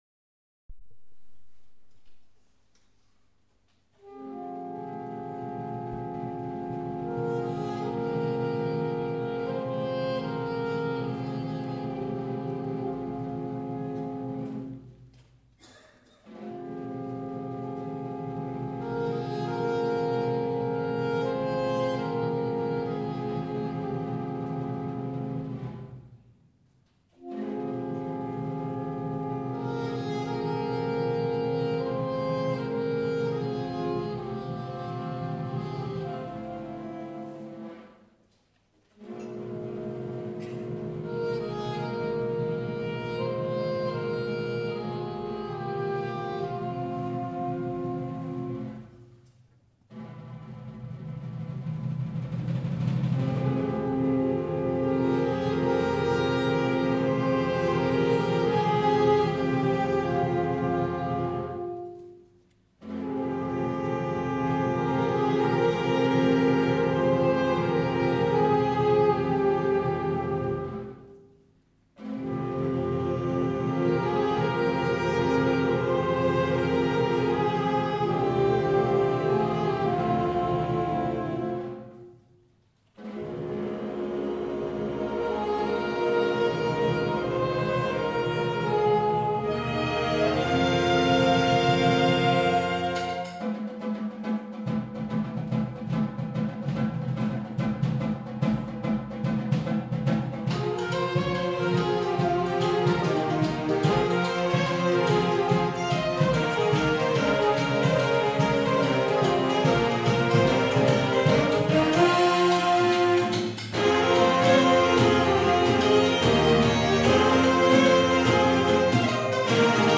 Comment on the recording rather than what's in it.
A Concert of Wind, Brass and Percussion, April 2015